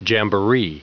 Prononciation du mot jamboree en anglais (fichier audio)
jamboree.wav